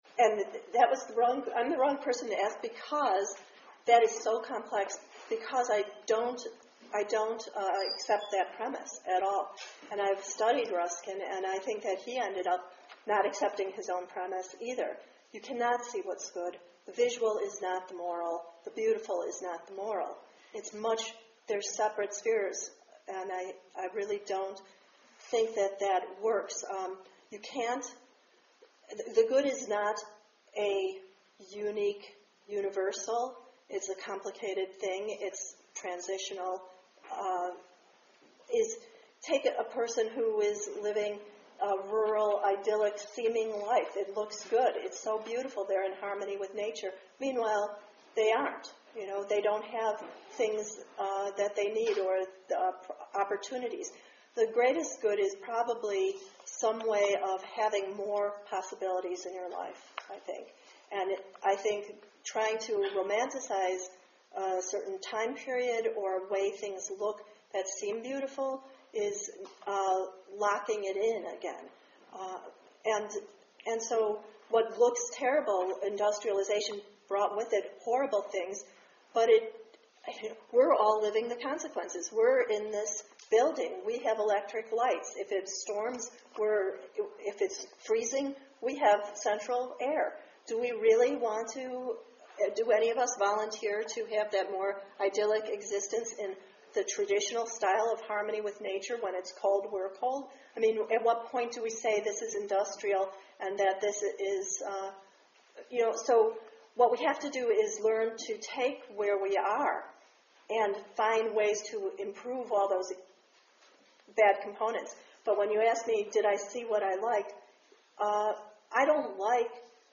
Given May 5, 2012 at the Campus Arts Center.    part 1 46min 13MB   part 2 44min 13MB